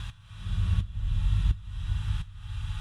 Index of /musicradar/sidechained-samples/170bpm